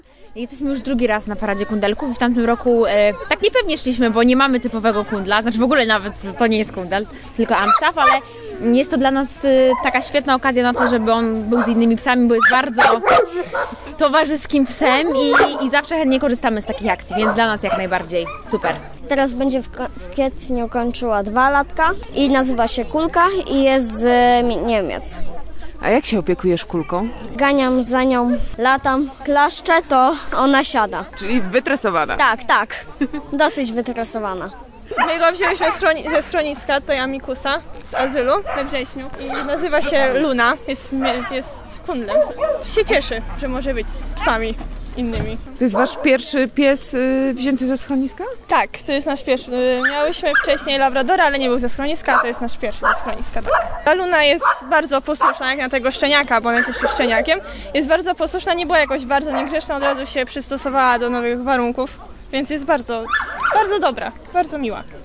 A jak właścicielom psów podobała się impreza? Posłuchajcie
1023_sonda_piese_ki_.mp3